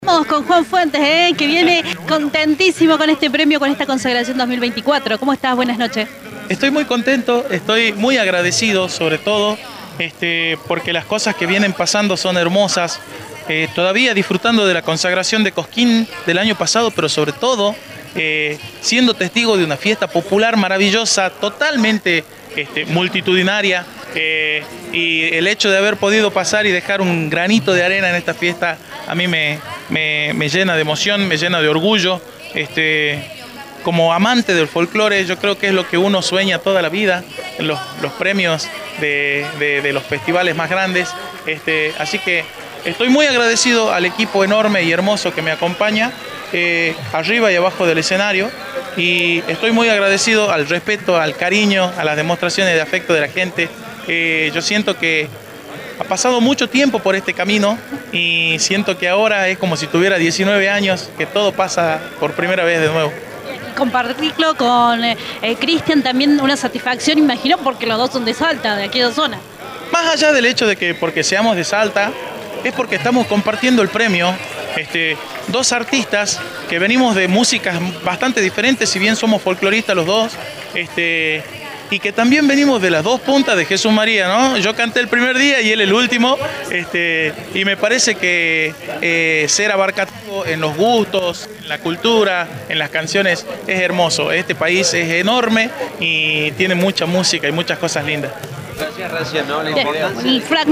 En la noche final del festival, hablamos con cada uno de los protagonistas, los consagrados, autoridades y artistas que pasaron por la última noche de color y coraje.